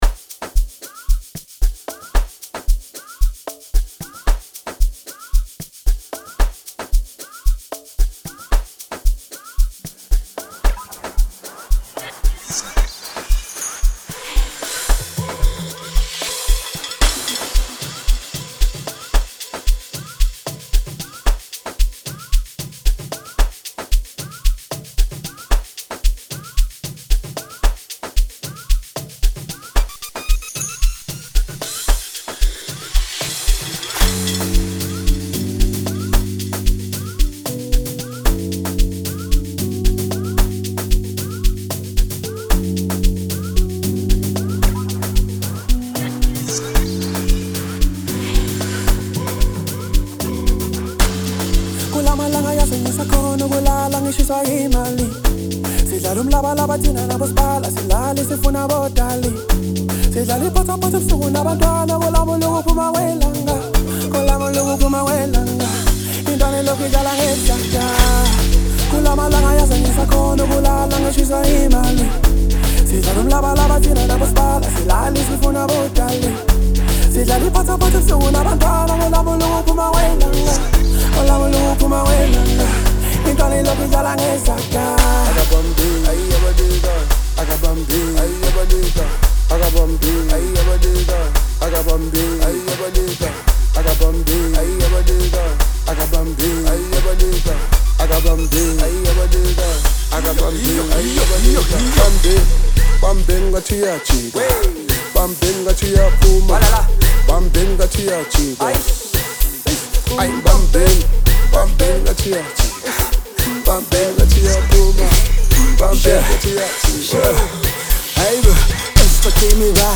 Song Genre: Amapiano.